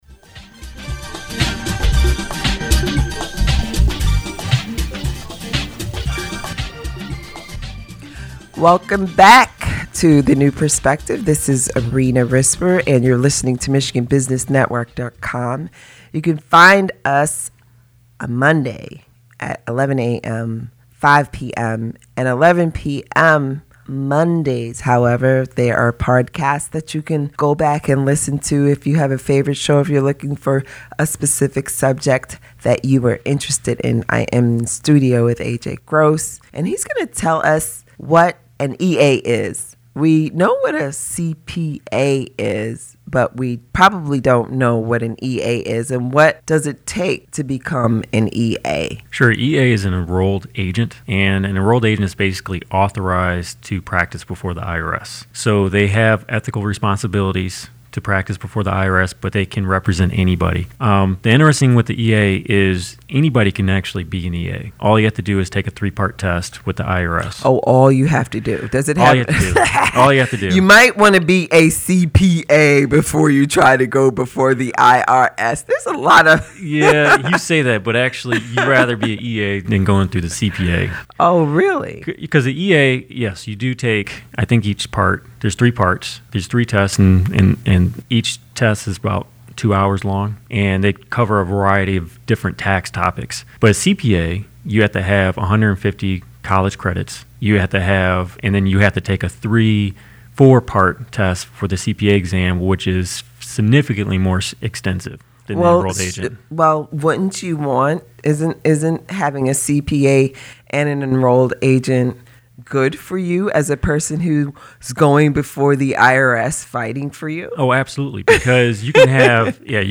The New Perspective is an internet radio show broadcasted on the Michigan Business Network.
IRS-Tax-Interview-SM4-14June30.mp3